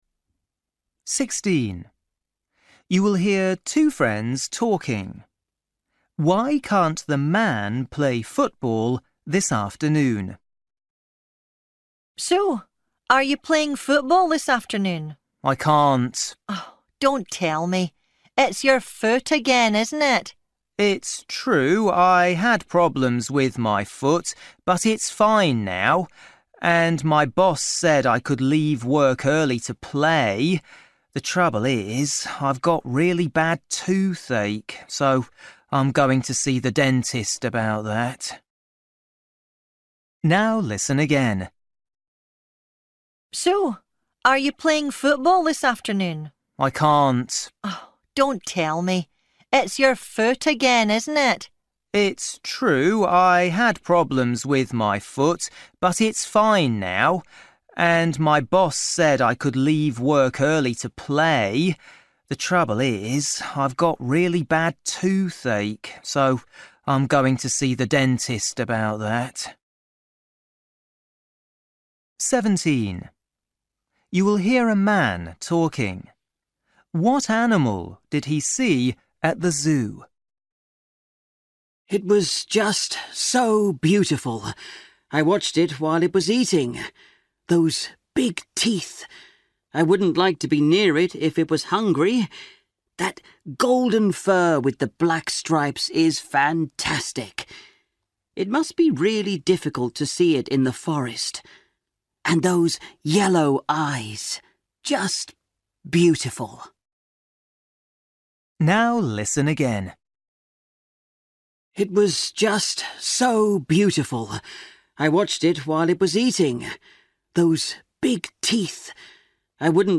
Listening: everyday short conversations
16   You will hear two friends talking.
17   You will hear a man talking.
19   You will hear a woman talking.
20   You will hear two friends talking about cooking dinner.